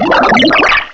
cry_not_reuniclus.aif